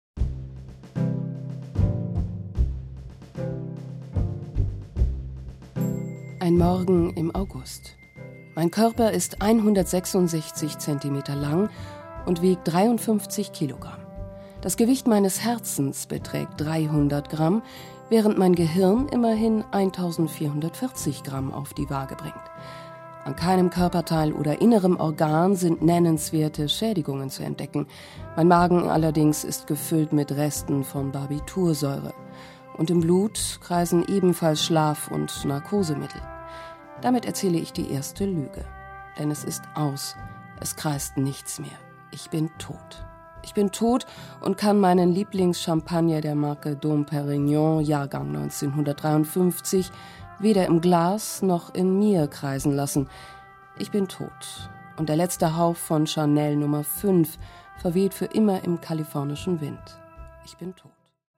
Profi-Sprecherin aus Berlin.
berlinerisch
Sprechprobe: eLearning (Muttersprache):